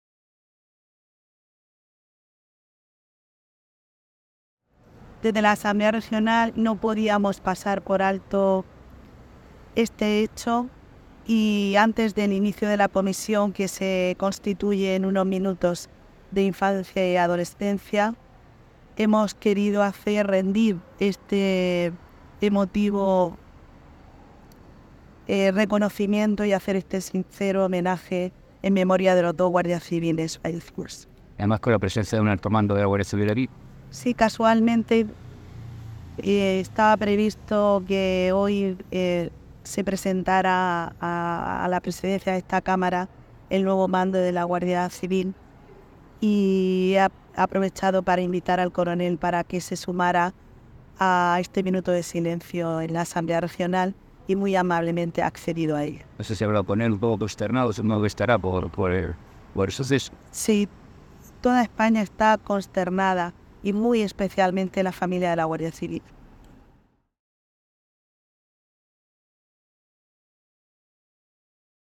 Minuto de silencio a las puertas de la Asamblea en homenaje a los dos guardia civiles fallecidos en Barbate.